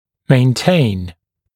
[meɪn’teɪn][мэйн’тэйн]поддерживать, сохранять